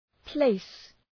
Προφορά
{pleıs}